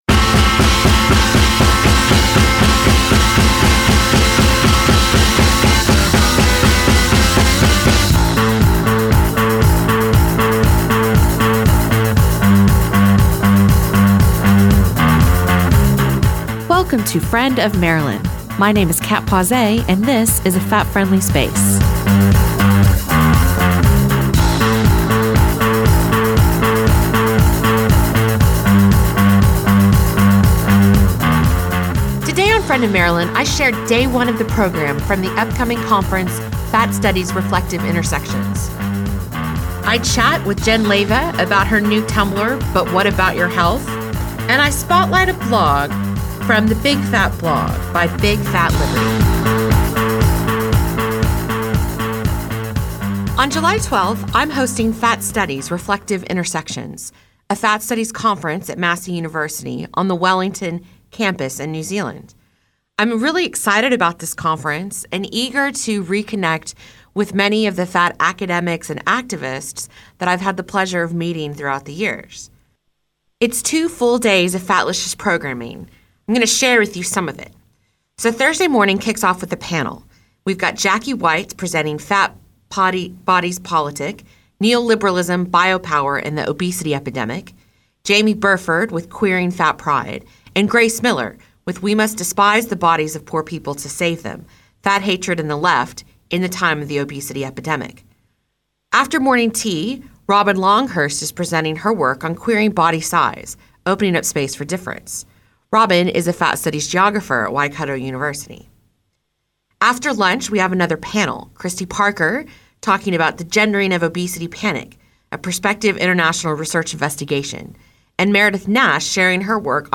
access radio